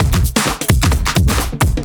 OTG_TripSwingMixA_130a.wav